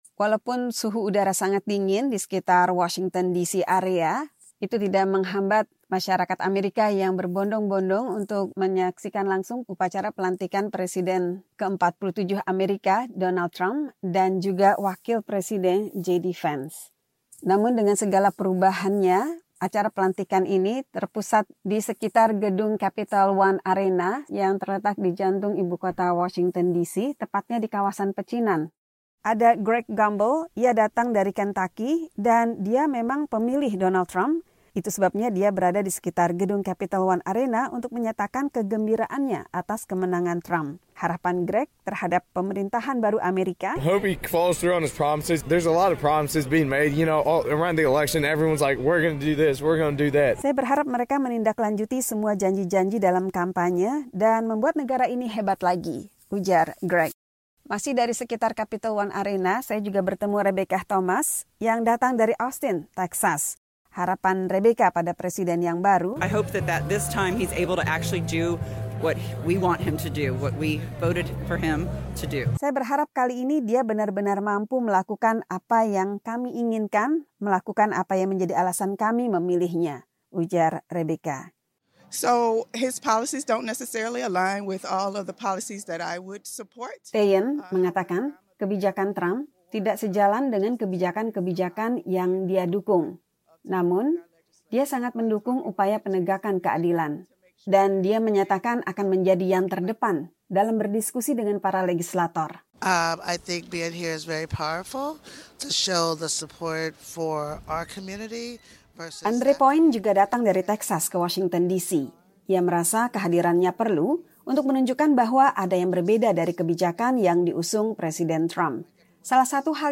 mewawancarai warga di sekitar Capital One Arena, Washington, DC tentang harapan mereka terhadap Presiden ke-47 Amerika Serikat.